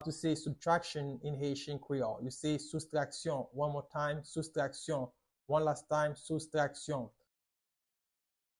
Pronunciation:
Listen to and watch “soustraskyon” audio pronunciation in Haitian Creole by a native Haitian  in the video below:
30.How-to-say-Subtraction-in-Haitian-Creole-–-soustraskyon-with-pronunciation.mp3